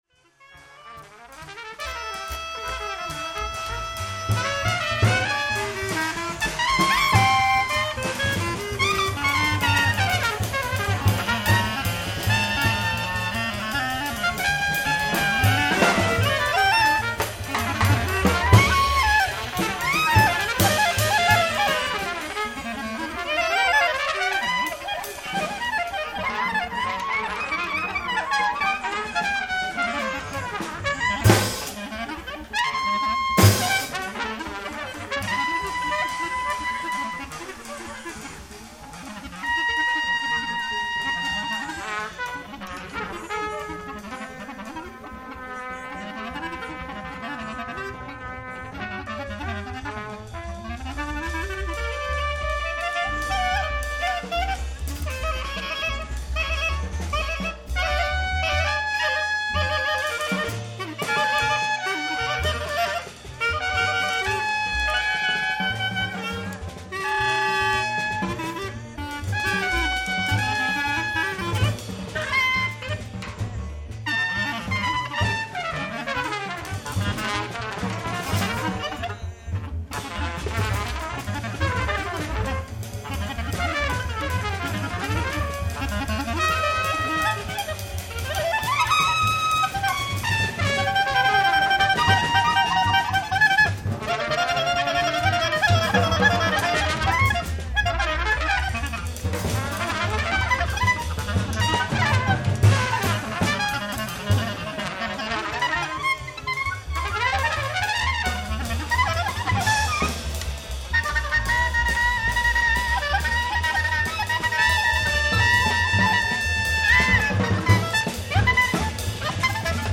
ts, cl, bcl
tp, slide tp
Recorded in concert at Glenn Miller Café, Stockholm